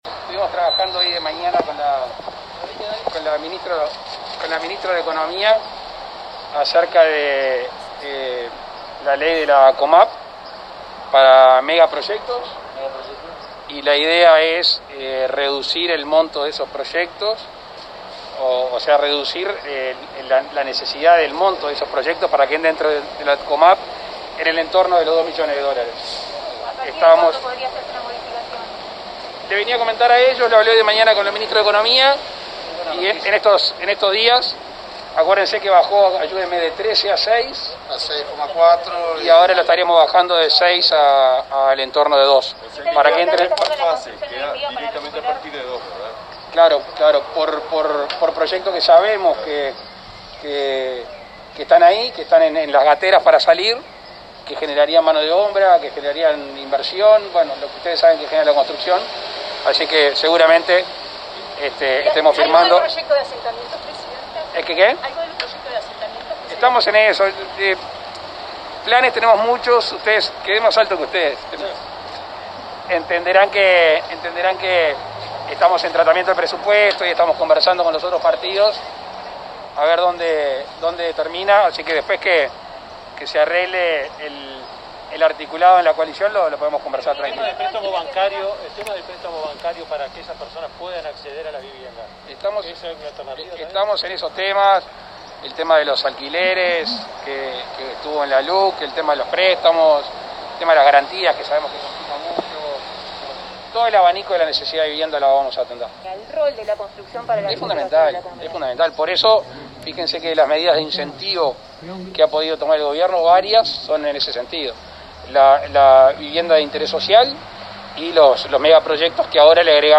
Como forma de estimular la inversión privada en el sector de la construcción, el Poder Ejecutivo estudia la posibilidad de disminuir de 6 millones de dólares a 2 millones, el monto de dinero exigido para presentar proyectos ante la Comisión de Aplicación de la Ley de Inversiones (COMAP). Así lo informó el presidente Luis Lacalle Pou durante un almuerzo con directivos de la Asociación de Promotores Privados de la Construcción.